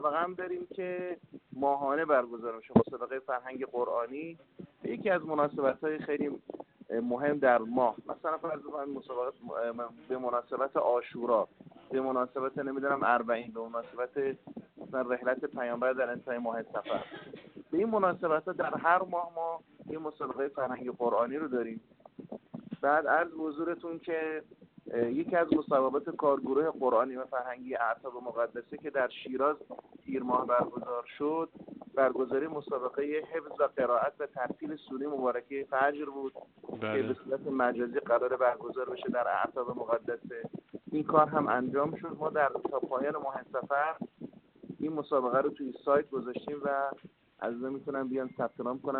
در گفت‌و‌گو با خبرنگار ایکنا، با اشاره به استقبال چشمگیر متقاضیان شرکت در دوره‌های آموزشی این مرکز گفت